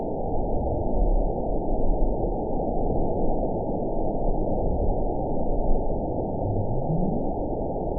event 911219 date 02/18/22 time 04:28:00 GMT (3 years, 3 months ago) score 9.39 location TSS-AB04 detected by nrw target species NRW annotations +NRW Spectrogram: Frequency (kHz) vs. Time (s) audio not available .wav